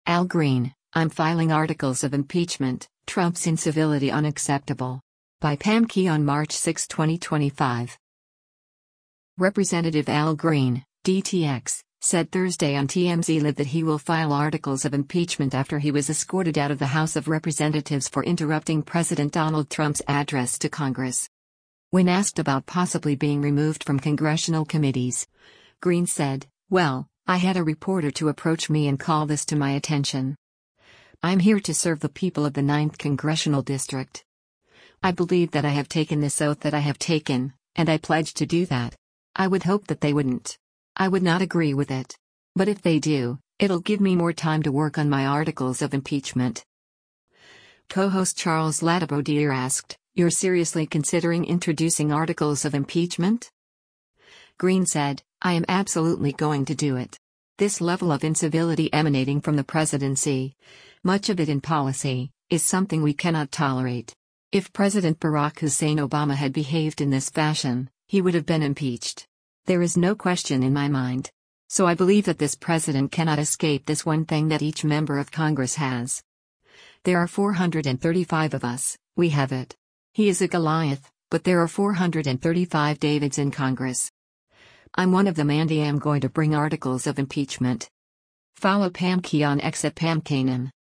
Representative Al Green (D-TX) said Thursday on “TMZ Live” that he will file articles of impeachment after he was escorted out of the House of Representatives for interrupting President Donald Trump’s address to Congress.